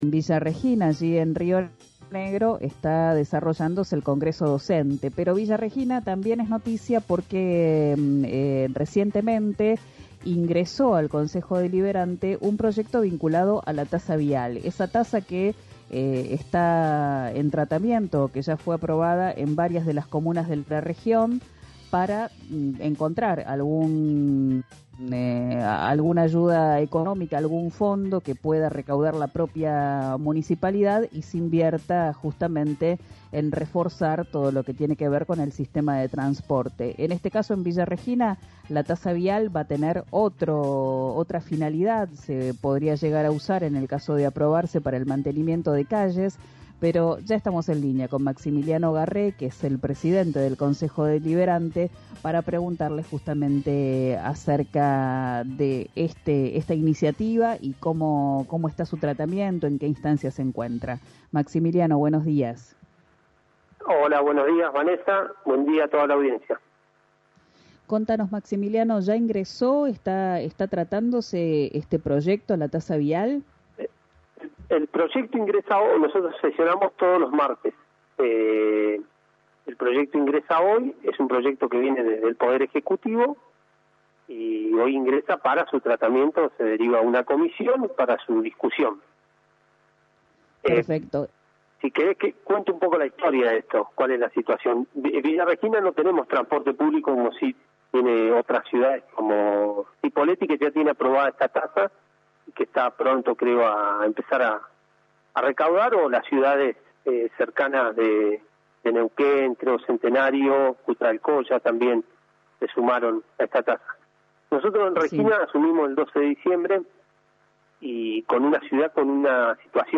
Escuchá a Maximiliano Garré, presidente del Concejo Deliberante, en RÍO NEGRO RADIO: